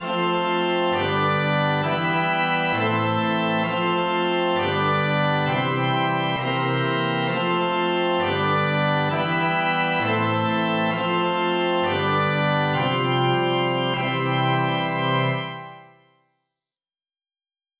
MPEG ADTS, layer III, v2, 128 kbps, 16 kHz, Monaural
「アニソン風」